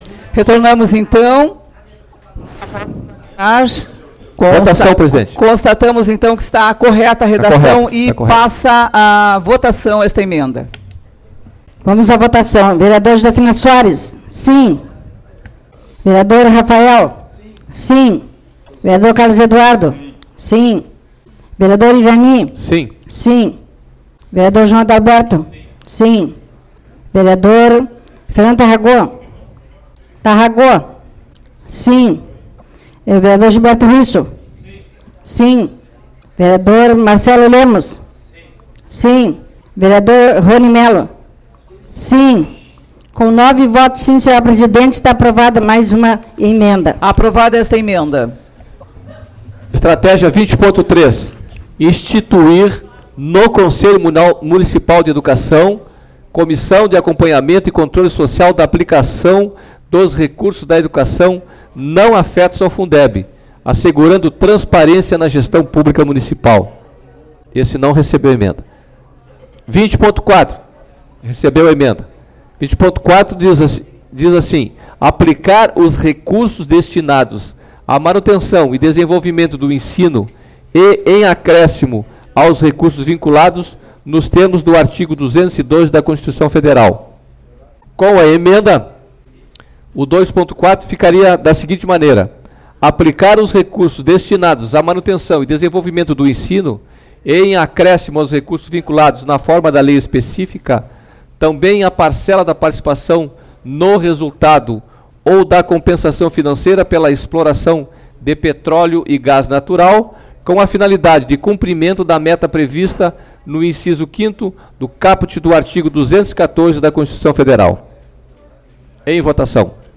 Sessão 10/12/2015